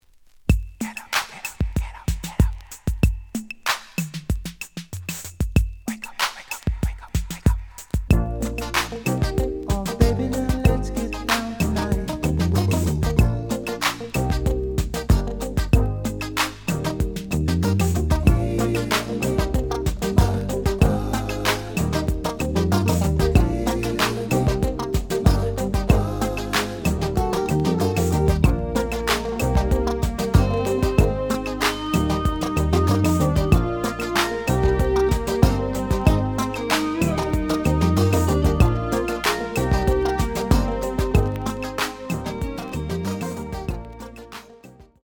The audio sample is recorded from the actual item.
●Genre: Soul, 80's / 90's Soul
Slight edge warp.